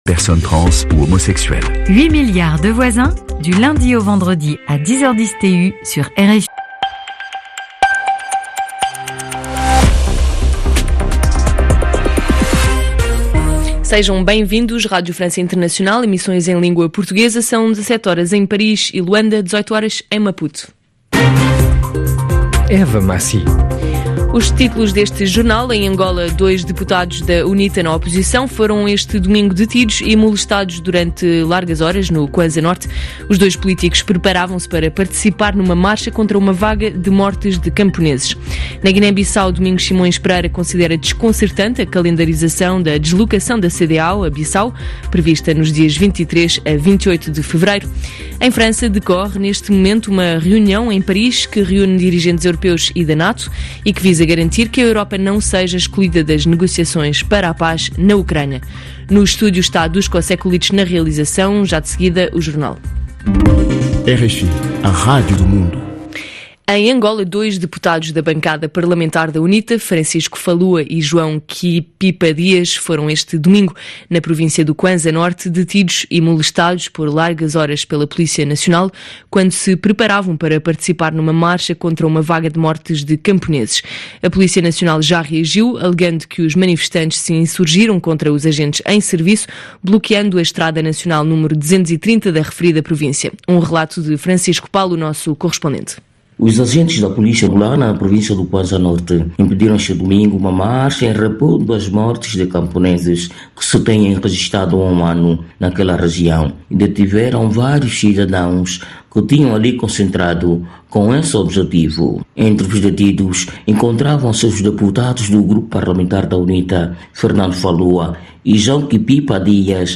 Ouça o jornal